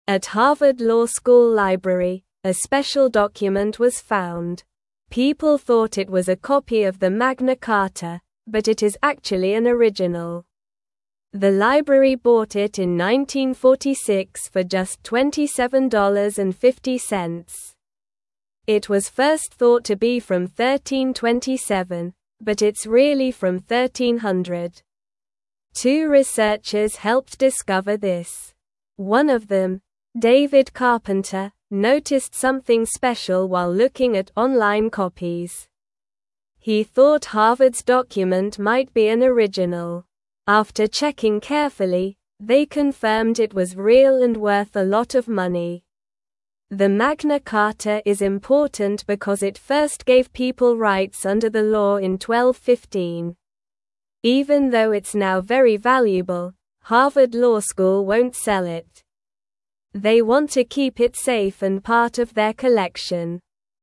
Slow
English-Newsroom-Lower-Intermediate-SLOW-Reading-Harvard-Finds-Special-Old-Paper-Called-Magna-Carta.mp3